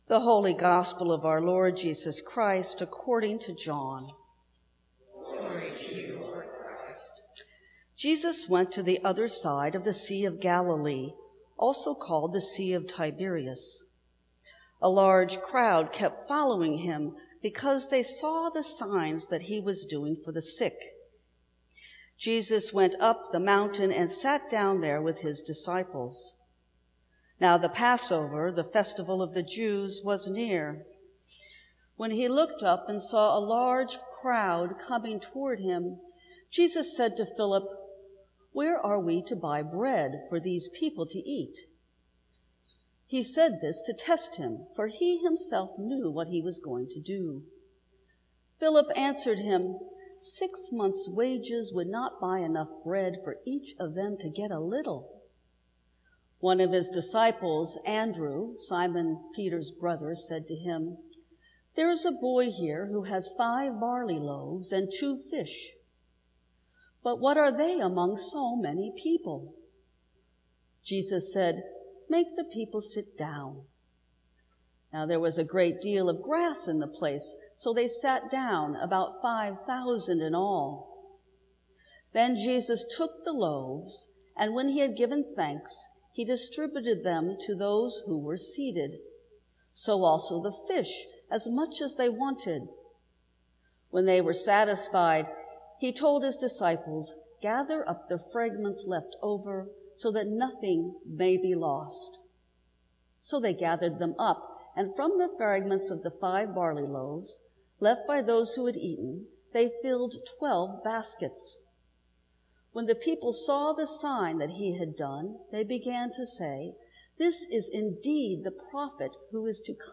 Sermons from St. Cross Episcopal Church Abundance Aug 02 2018 | 00:14:28 1x 00:00 / 00:14:28 Subscribe Share Apple Podcasts Spotify Overcast RSS Feed Share Link Embed